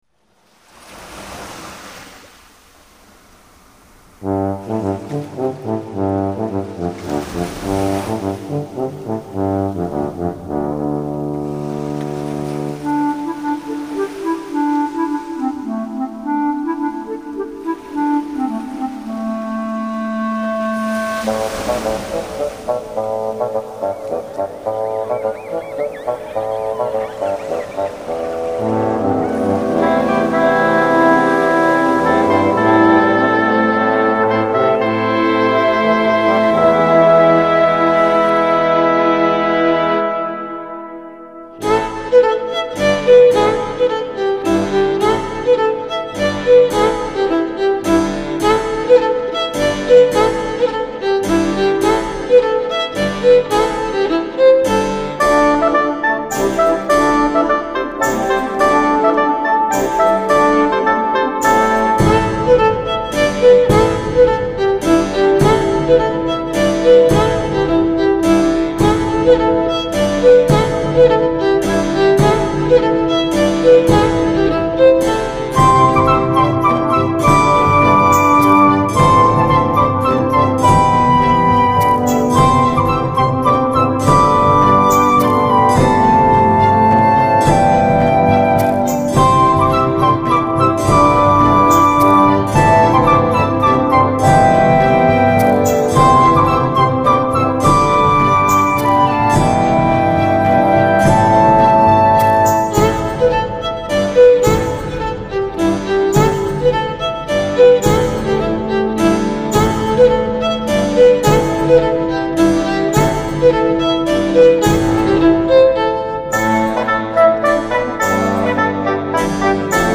浪漫新世纪音乐